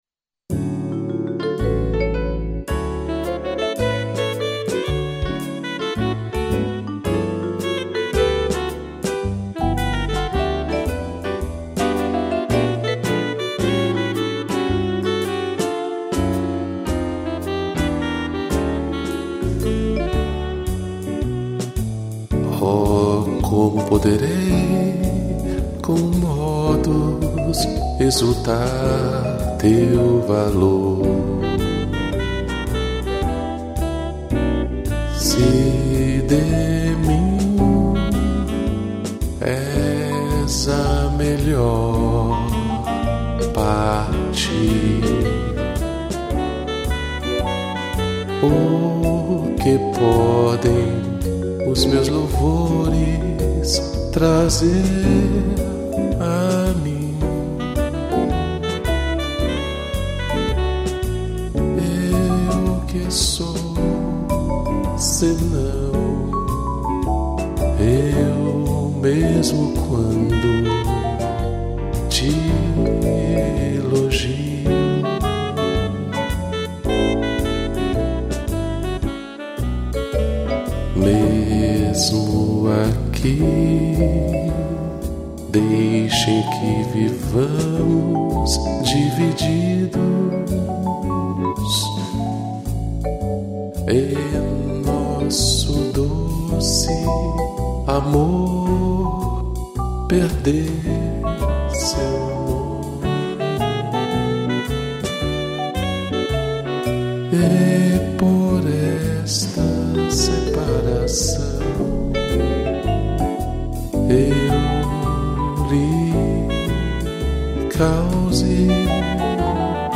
piano, vibrafone e sax